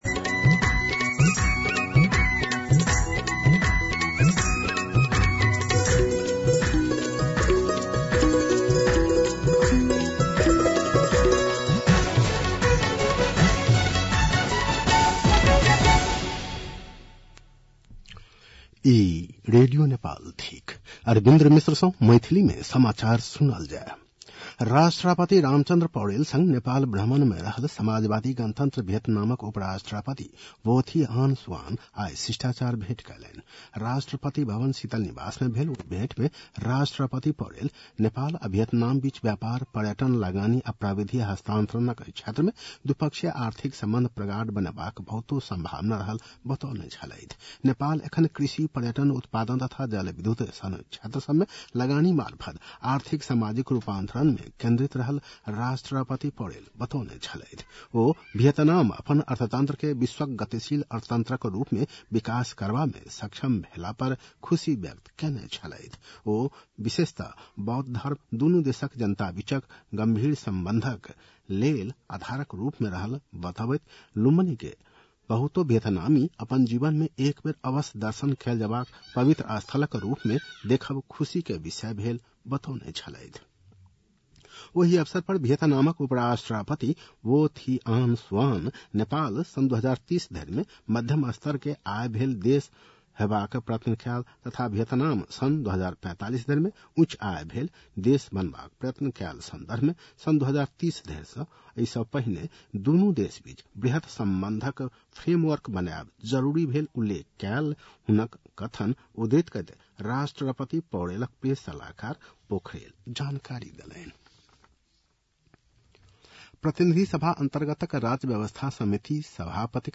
मैथिली भाषामा समाचार : ९ भदौ , २०८२
6.-pm-maithali-news-1-5.mp3